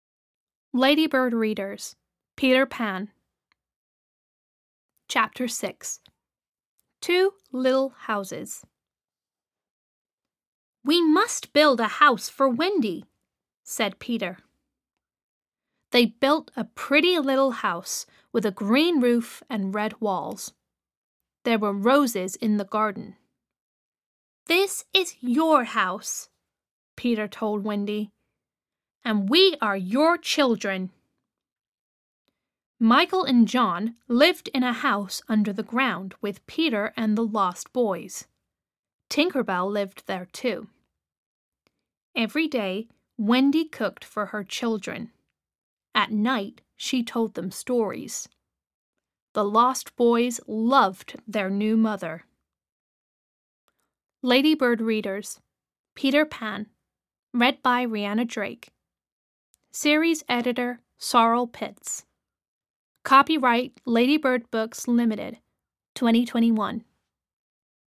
Audio US